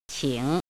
怎么读
qǐng